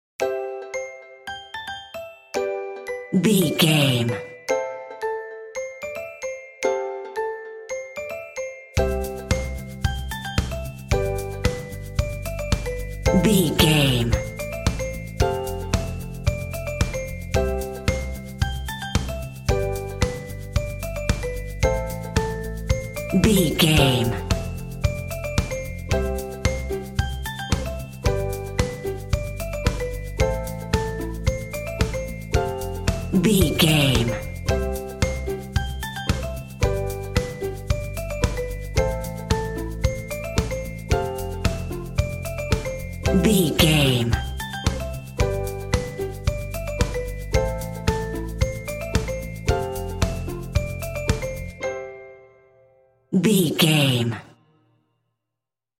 Ionian/Major
bouncy
cheerful/happy
playful
energetic
ukulele
bells
piano
percussion
drums
bass guitar